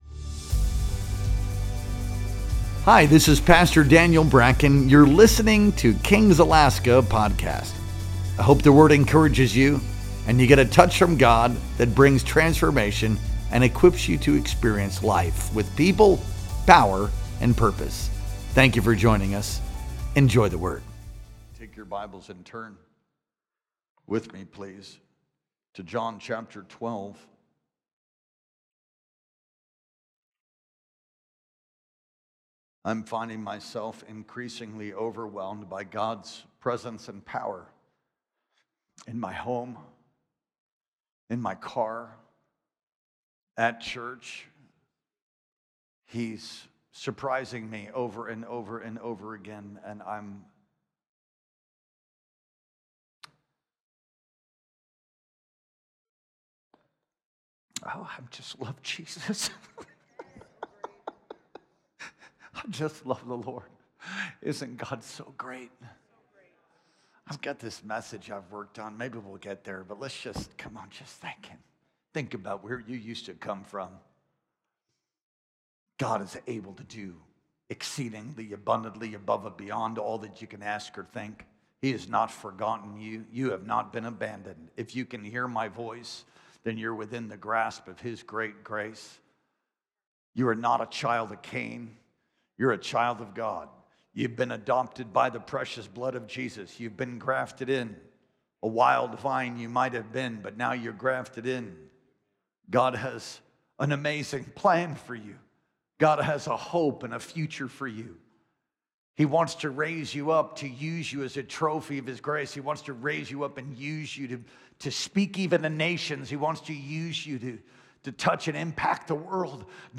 Our Sunday Night Worship Experience streamed live on April 13th, 2025.